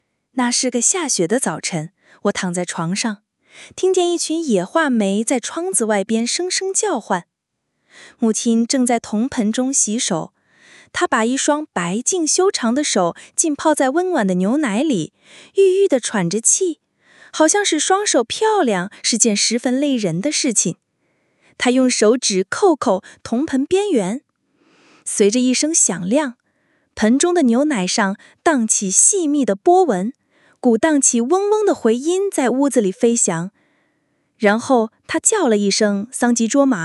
Gemini 25 Pro 预览版TTS.mp3